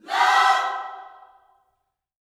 LOVECHORD1.wav